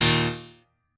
piano1_12.ogg